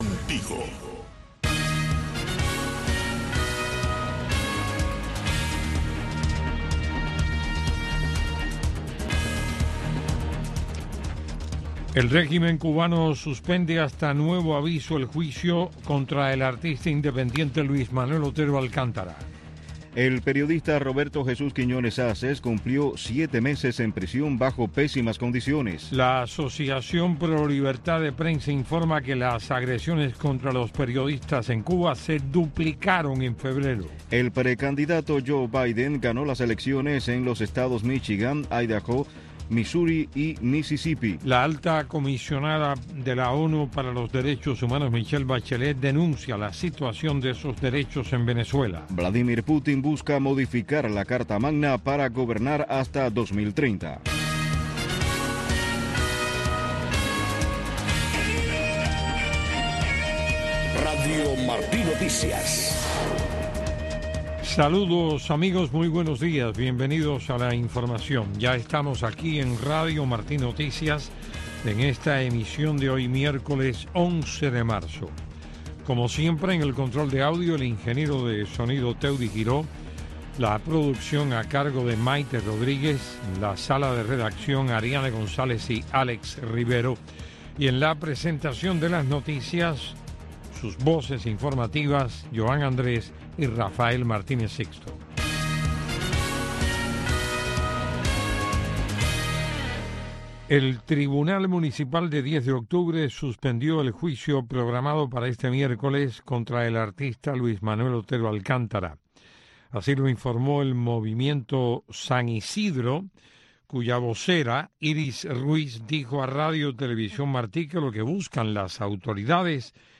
Noticiero de Radio Martí 6:00 AM